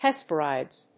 音節Hes・per・i・des 発音記号・読み方
/hespérədìːz(米国英語)/